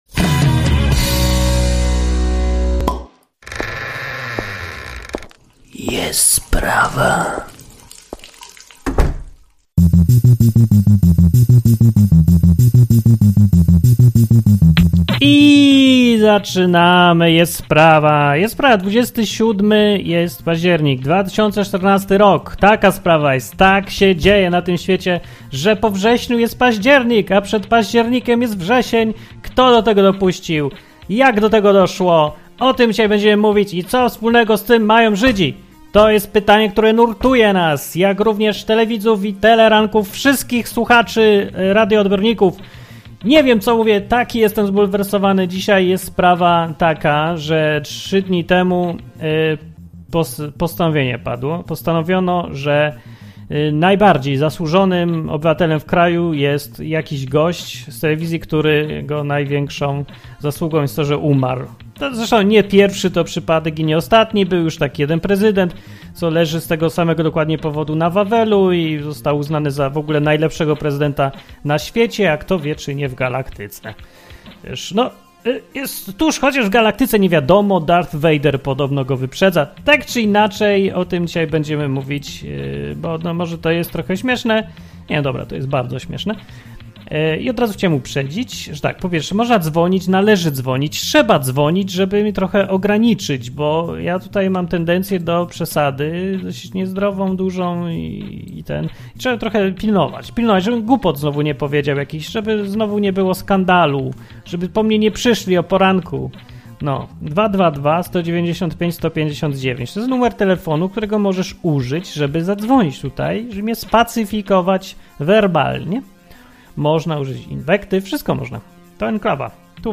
Audycja o bardzo poważnych sprawach, której się nie da prowadzić w poważny sposób.